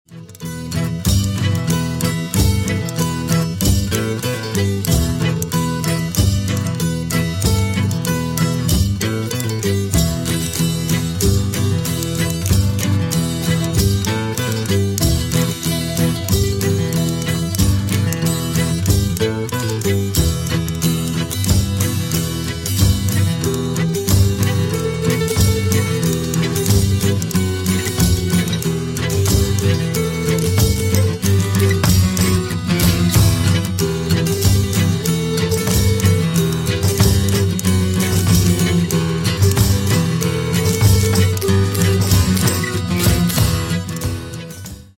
A mash up of traditional Celtic and Appalacian tunes.